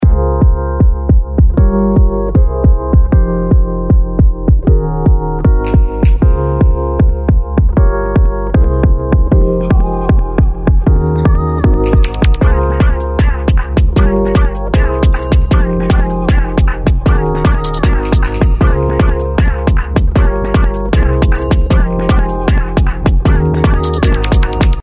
その他「Reverse Saturate」は汚しとリバースですが、程よいかかり具合と、使い勝手も良く、何か一つ足したい時など重宝するかと思います。
今回はキックにかけてみました。